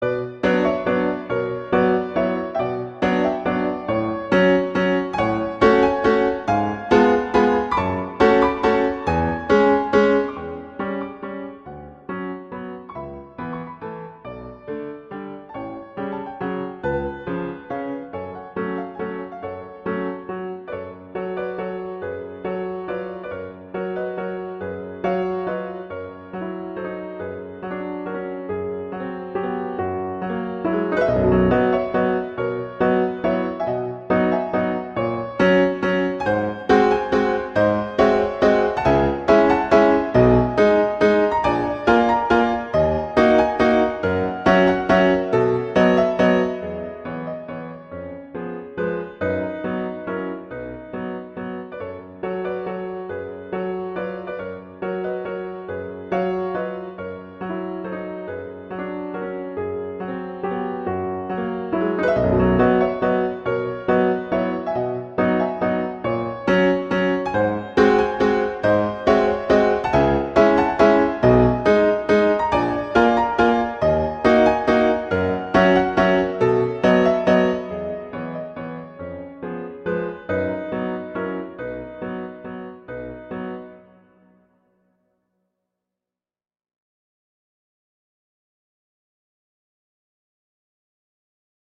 Instrumentation: piano solo
classical